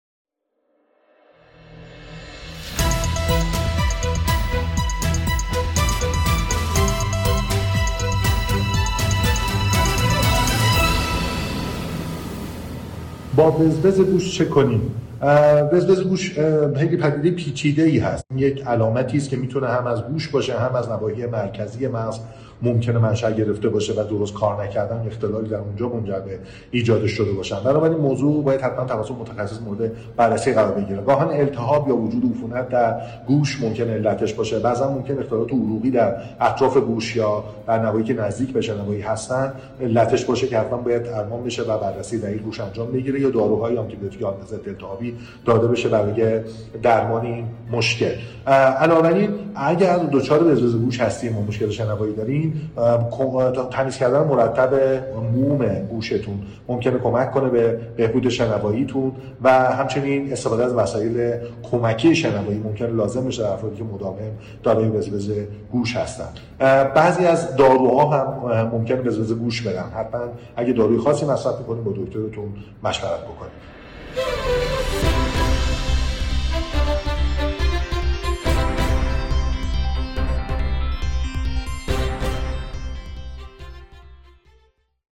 نوروفیزیولوژیست در رادیو ایمنا همراه ما باشید.